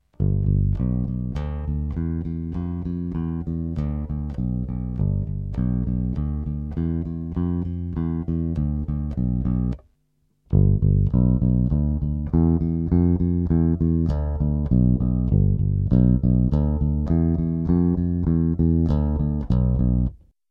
I recorded the examples via an EBS HD350 amplifier acting as a preamplifier and EQ.
The following clip demonstrates a bass with a switchable pickup, changing from single-coil to a dual-coil humbucker sound. Notice, when switching from single-coil to humbucker, the volume, especially in the low frequencies, increases.
Electric Bass: Single Then Humbucker
If you are listening on headphones, you may notice some background noise on the single-coil sound.
bt2_2_Electric_Bass_Single_Then_Humbucker.mp3